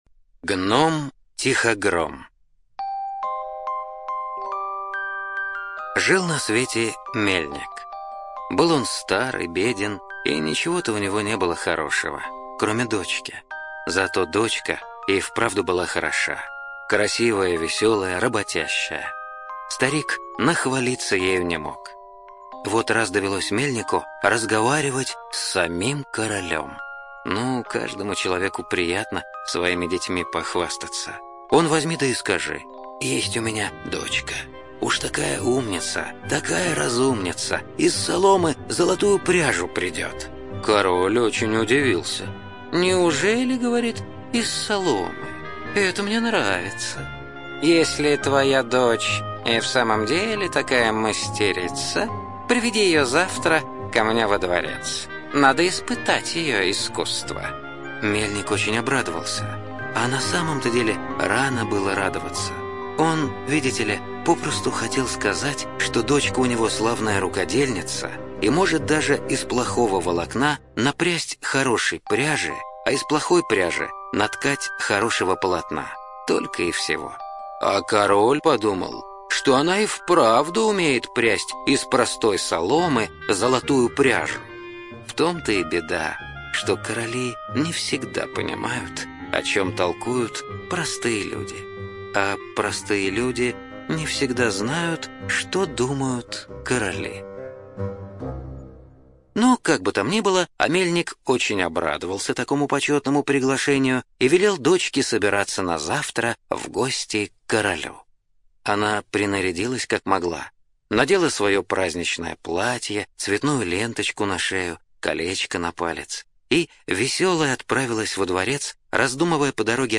Гном-Тихогром - аудиосказка братьев Гримм. Один мельник заявил государю, что его дочь обладает умением из обычной соломы прясть золото.